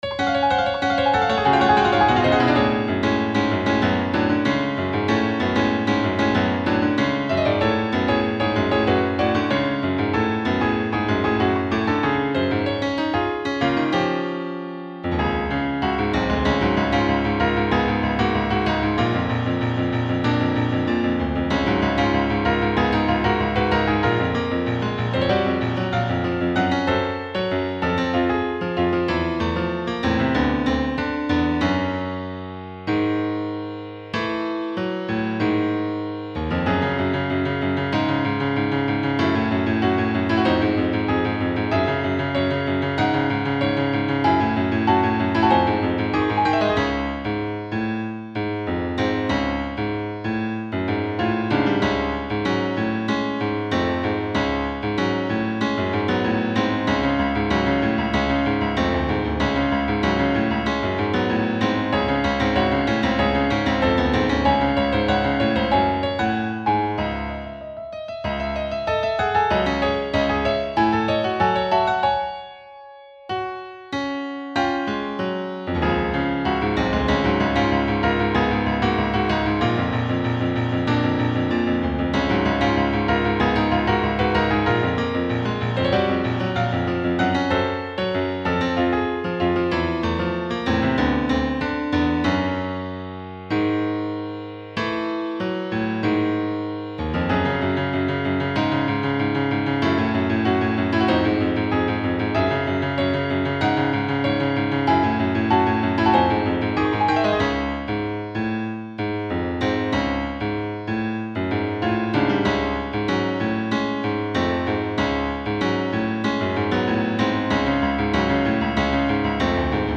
champion battle theme
the region is set in greece so i tried to make it sound... greecy??
rather than changing keys every few bars and doing random shit, i kept the tonic on d flat throughout the whole track and used accidentals and modal interchange to keep the track moving and change the mood and energy.